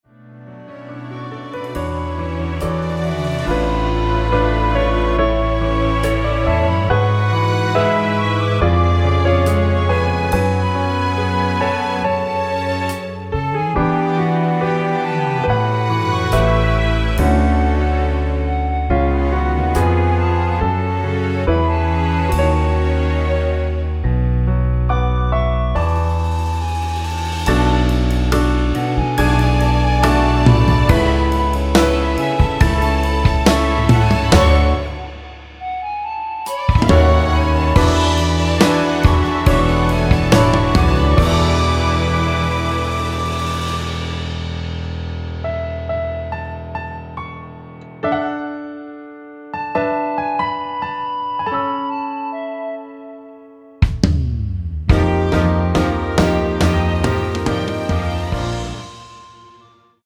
원키에서(+2)올린 멜로디 포함된(1절+후렴) 진행되게 편곡한 MR입니다.
앞부분30초, 뒷부분30초씩 편집해서 올려 드리고 있습니다.
중간에 음이 끈어지고 다시 나오는 이유는